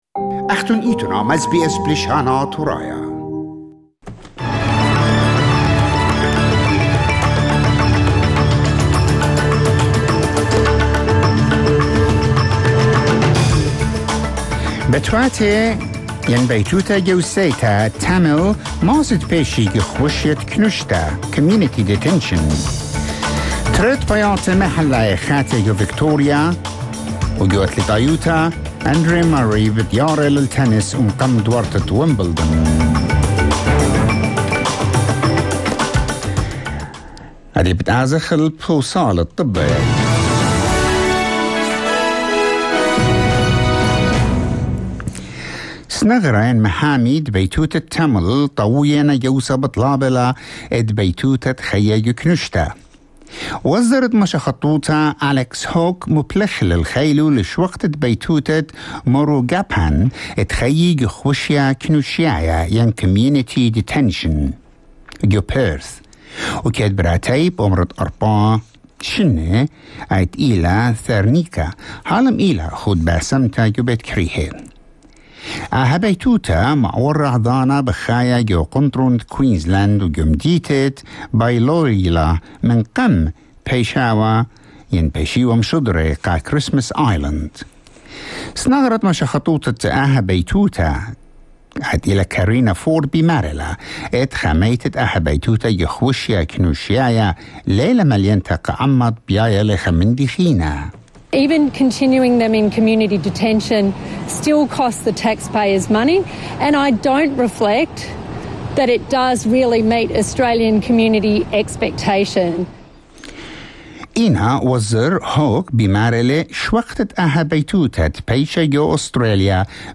SBS NEWS IN ASSYRIAN 16 June 2021